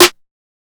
Raw SSL Snare.wav